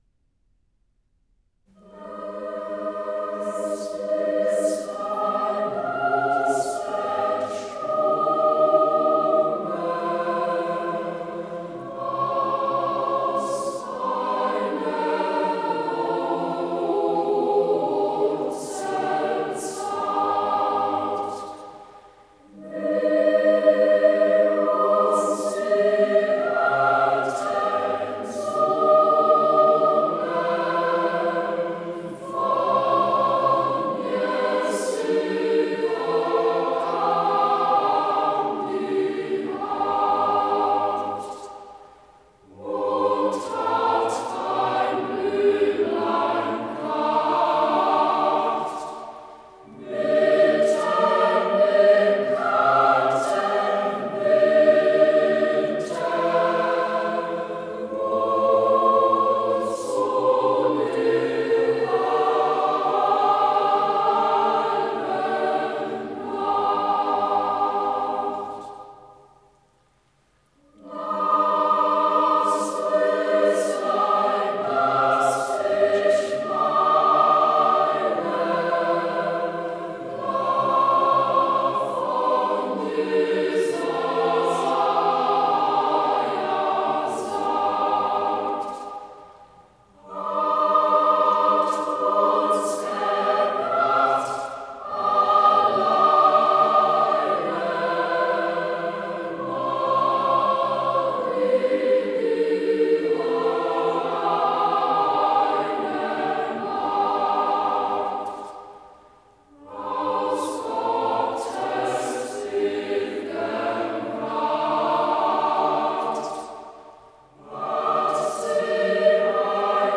Nikolaus) sowie eine Weihnachtsmeditation und als mp3 zwei Adventslieder.